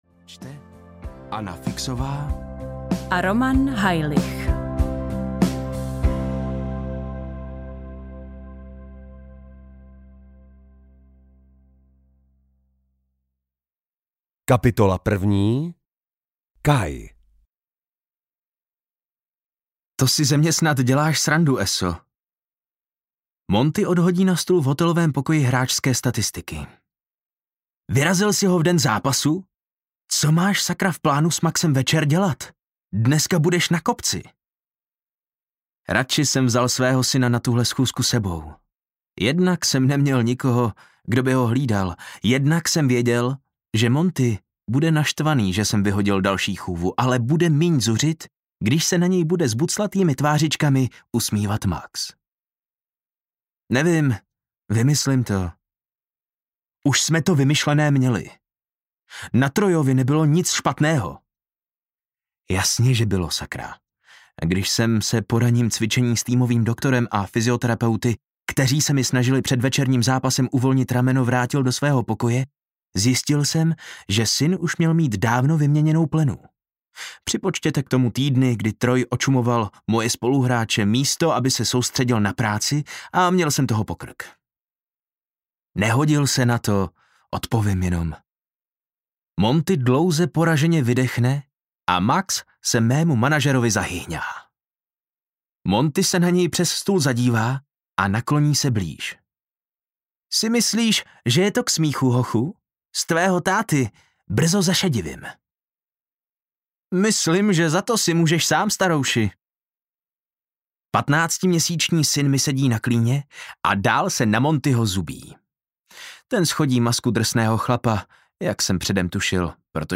Polapená audiokniha
Ukázka z knihy